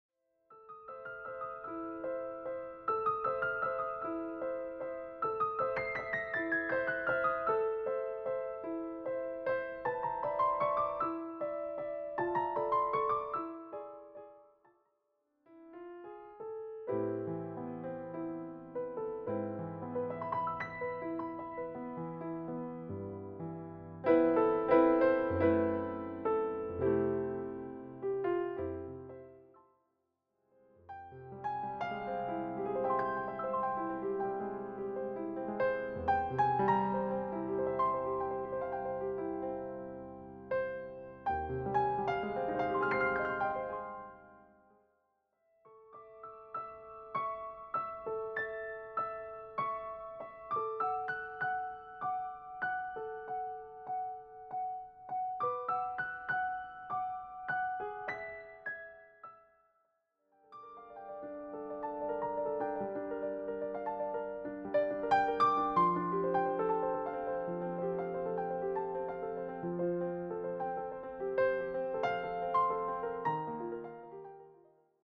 presented in a smooth piano setting.
steady, easygoing tone